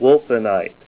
Help on Name Pronunciation: Name Pronunciation: Wulfenite + Pronunciation
Say WULFENITE Help on Synonym: Synonym: ICSD 26784   PDF 44-1486   Yellow Lead Ore